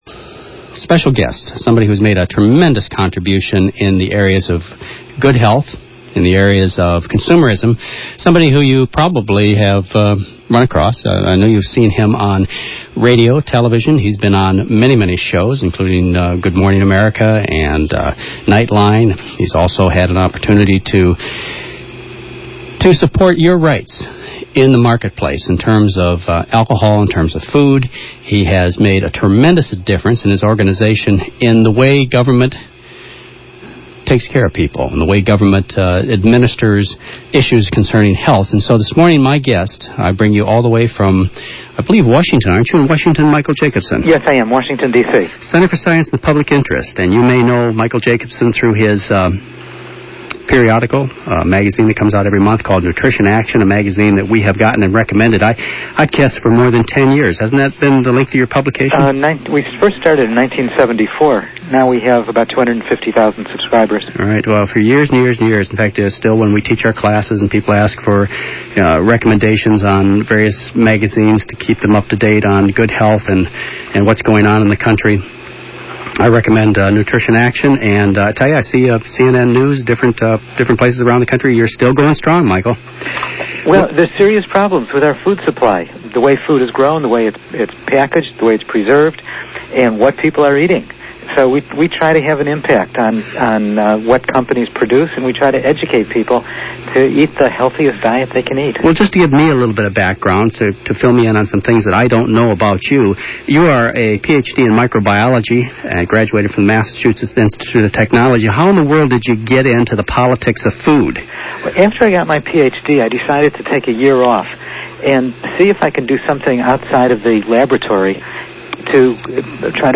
Michael Jacobson, PhD (taped interview)
Note: This taped interview from “Your Good Health” (5/19/92), hosted by Dr. McDougall has been edited.